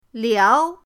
liao2.mp3